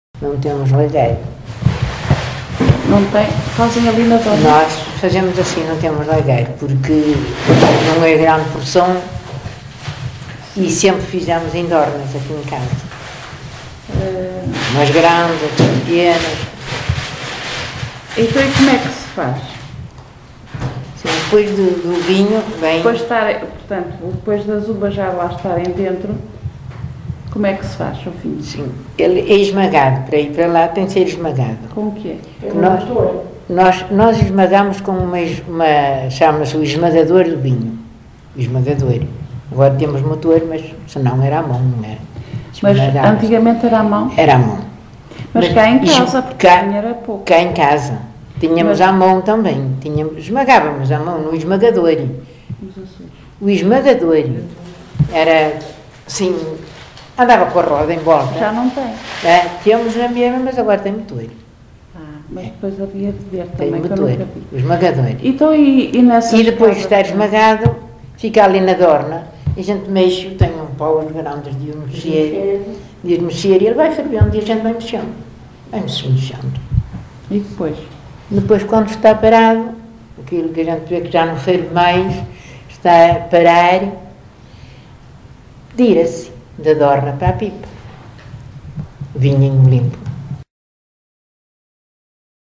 LocalidadeGião (Vila do Conde, Porto)